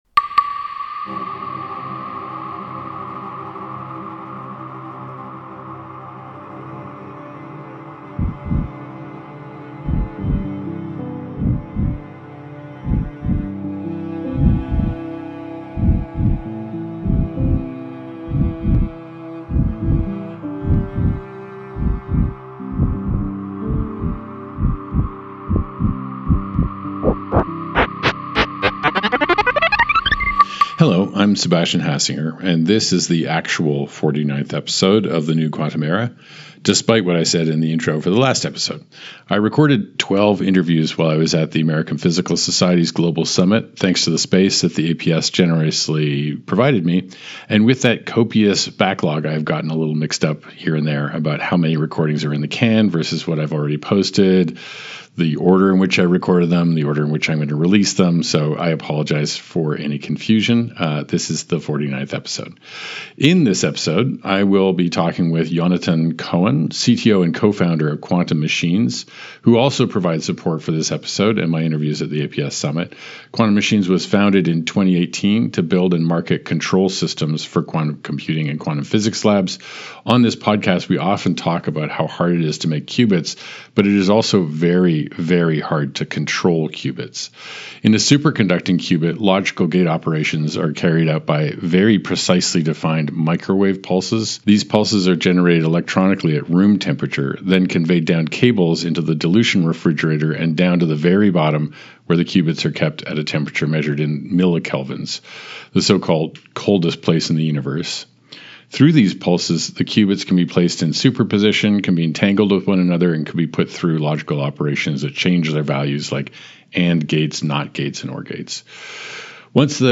As a pioneer in quantum control systems, Quantum Machines is at the forefront of tackling the critical challenges of scaling quantum computing, and they also provided support for my interviews conducted at the American Physical Society’s Global Summit 2025.